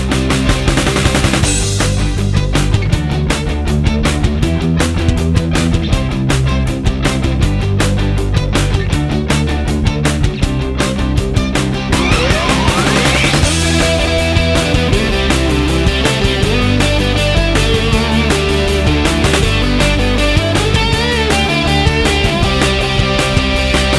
no Backing Vocals T.V. Themes 2:56 Buy £1.50